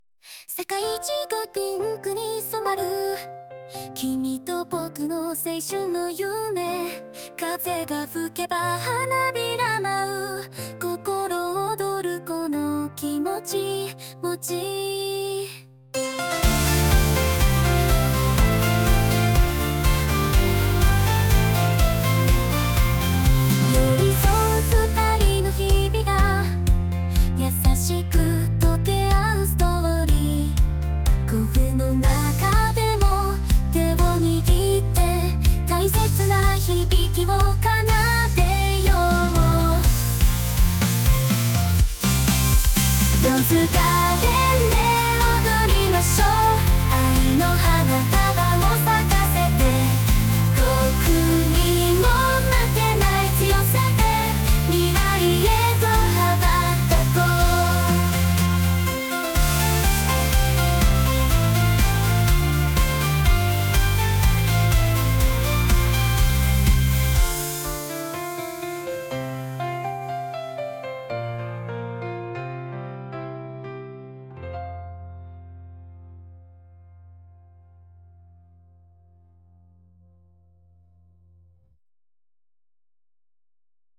ローズガーデンと強風
417_ローズガーデンと強風.mp3